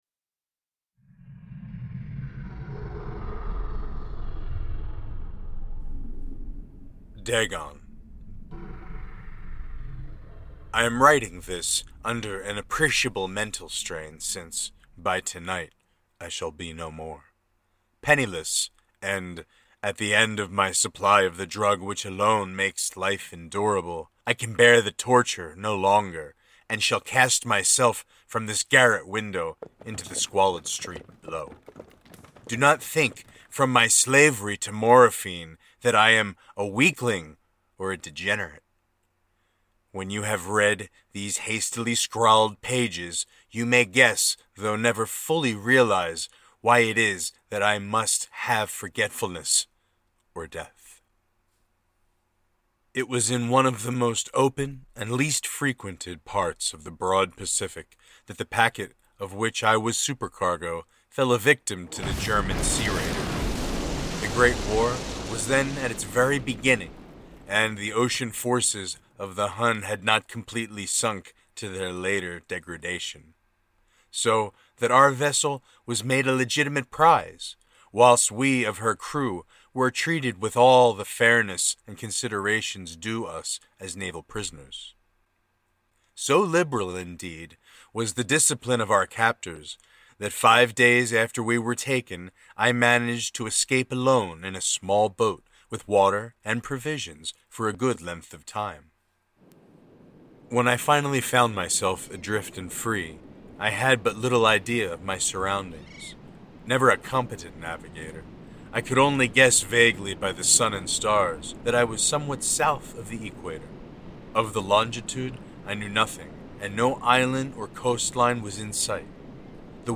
An Audio Theatrical Narration